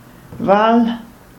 Vals ([fals], im Walsertiitsch Falsch [faɫʃ], rätoromanisch Val
[val]/?, uf Underländer-Schwizertütsch au Wals) isch e Gmaind i dr Region Surselva vom Kanton Graubünde.
Roh-sursilvan-Val.ogg.mp3